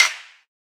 soft-hitclap.ogg